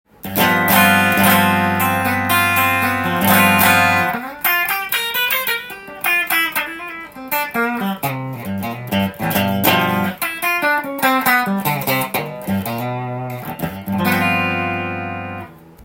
試しに弾いてみました
リアピックアップで弾いてみました。
レトロな音の中に深みを感じる気がしました。
磁力が薄まった枯れた音がするのも特徴です。